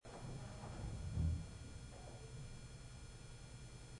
EVPs
The recorder had been left at “base” (gift shop) all night and never moved. No one was in the room at the time this EVP was caught.
daddy-no-one-down-in-basement-it-was-break-down-time-basement.mp3